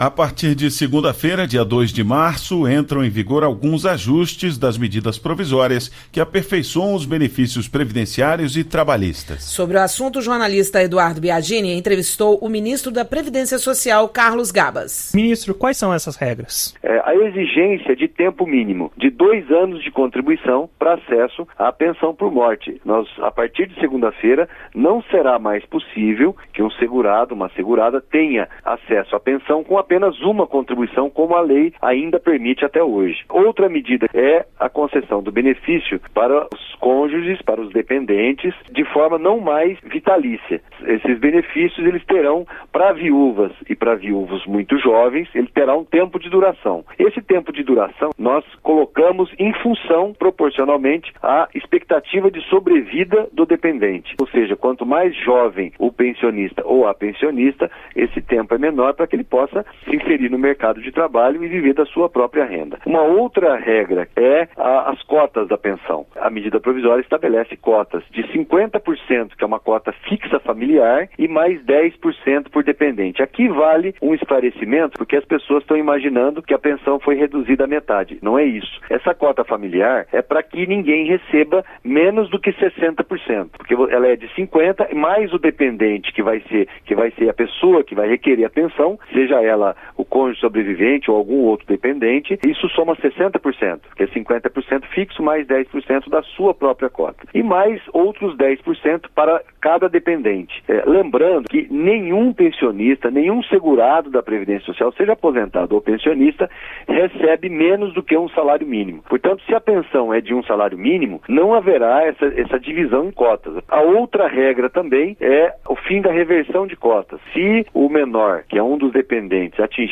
entrevista-carlos-gabas-sexta.mp3